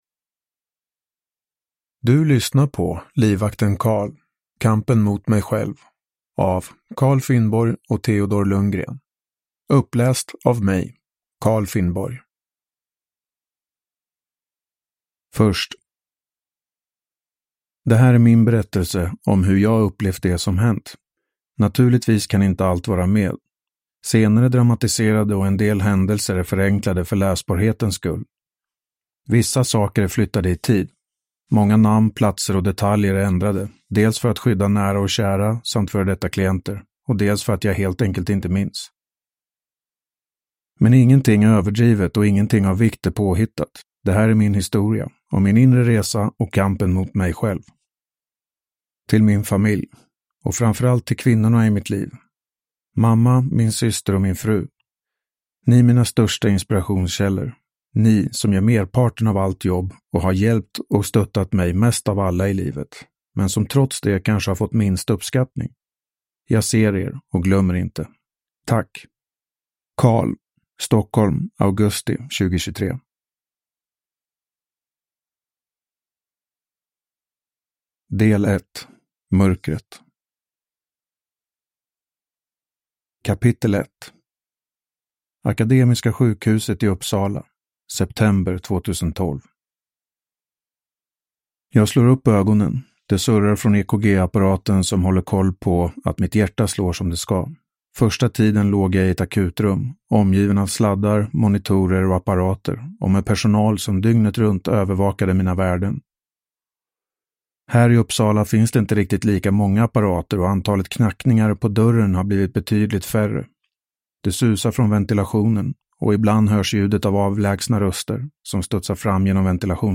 Ljudbok